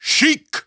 The announcer saying Sheik's name in English and Japanese releases of Super Smash Bros. Brawl.
Sheik_English_Announcer_SSBB.wav